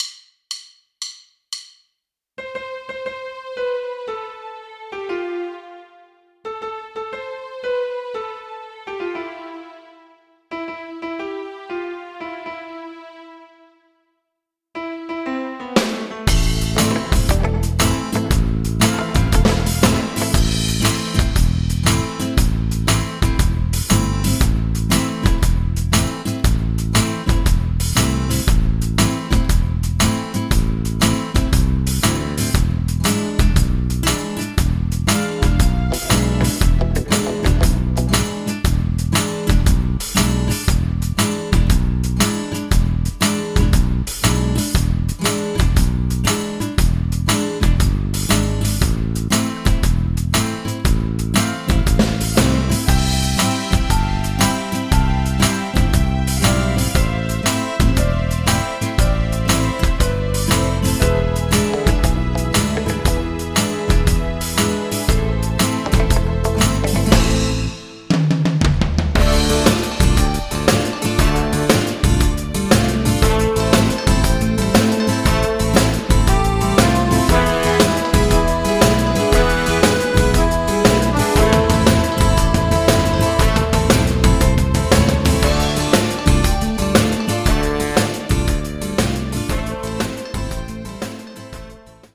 instrumental, karaoke